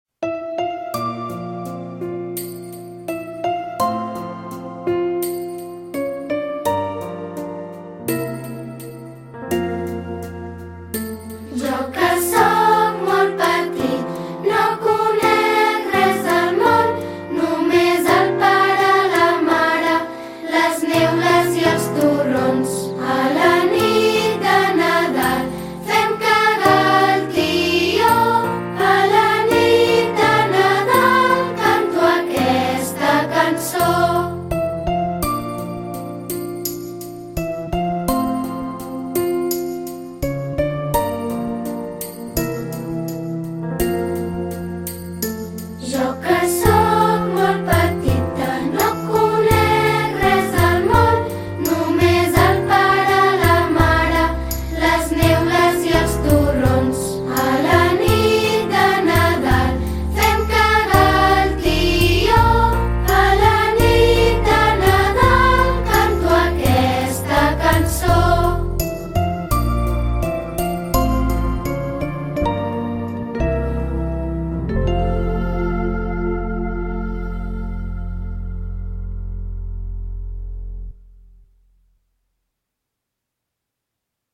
Concert de NADAL
Avui, com a cloenda de totes les activitats nadalenques que hem anat realitzant, hem fet el Concert de Nadal.